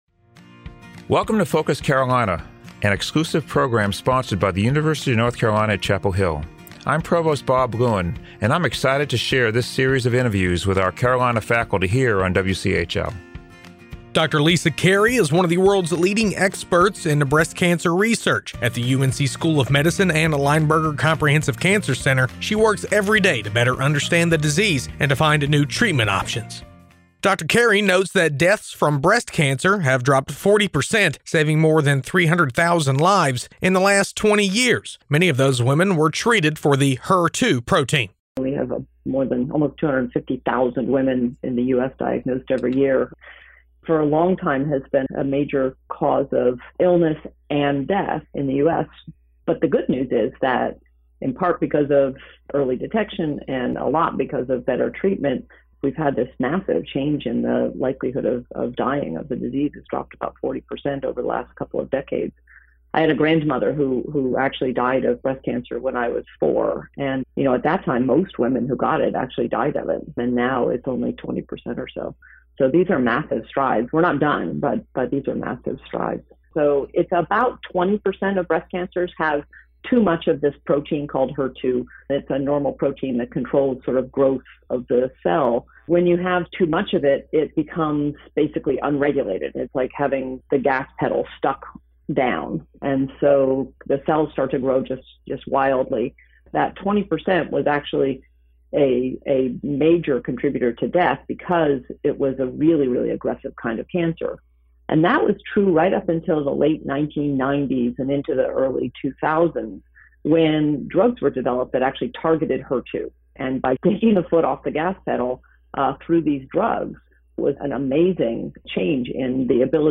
Tune in to Focus Carolina during morning, noon and evening drive times and on the weekends to hear stories from faculty members at UNC and find out what ignites their passion for their work. Focus Carolina is an exclusive program on 97.9 The Hill WCHL, sponsored by the University of North Carolina at Chapel Hill.